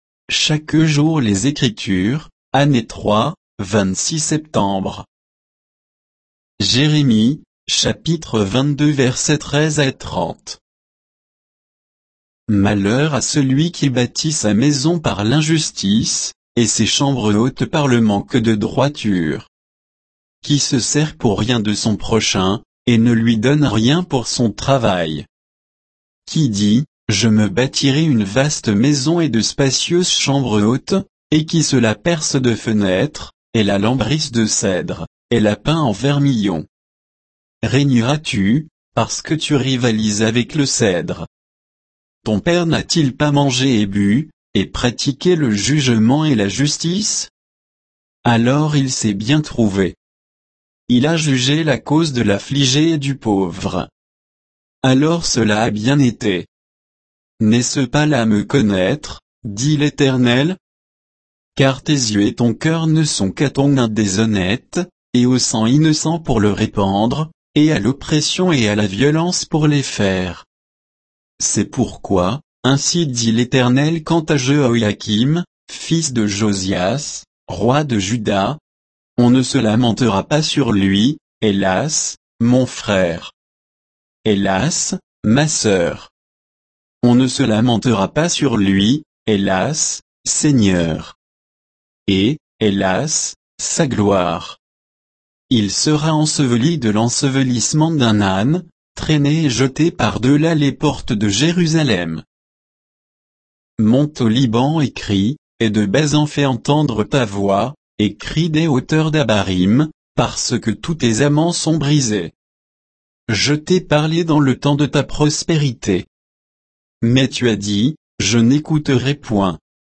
Méditation quoditienne de Chaque jour les Écritures sur Jérémie 22